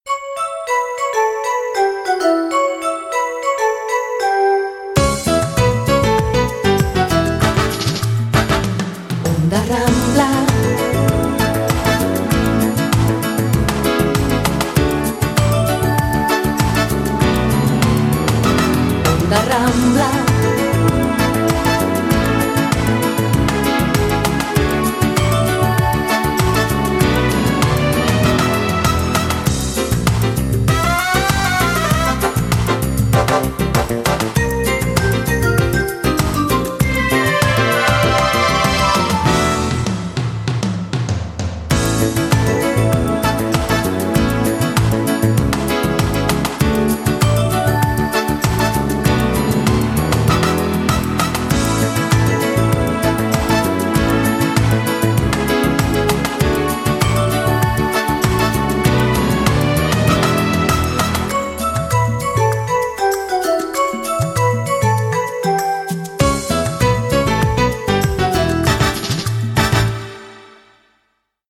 Indicatiu cantat emissora